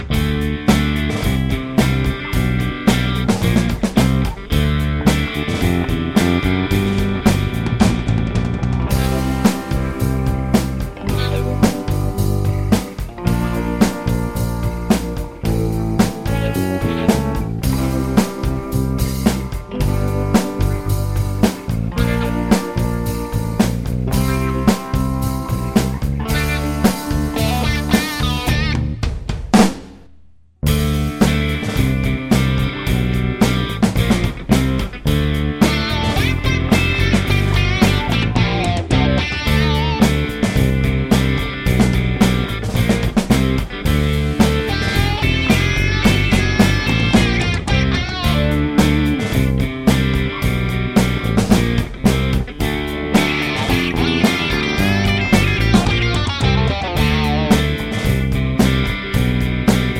no Backing Vocals Blues 4:08 Buy £1.50